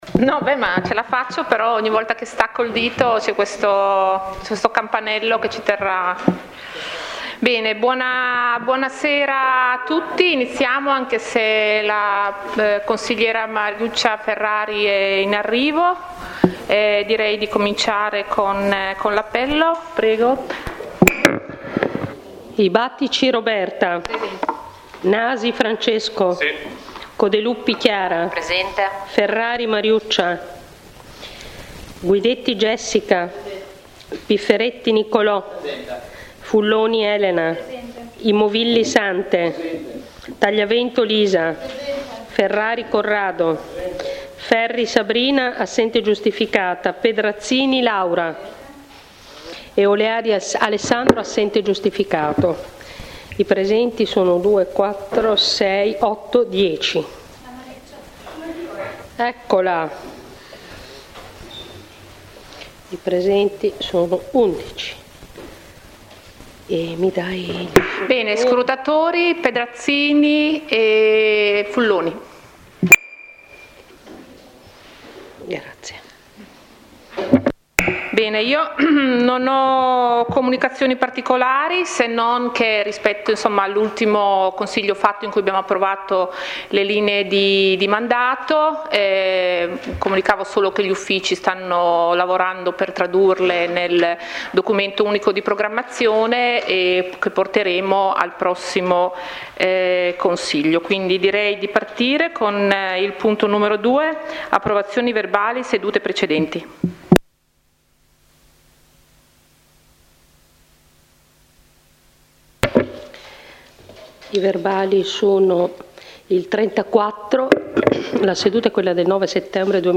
Consigli comunali 2023/2024 – Registrazione audio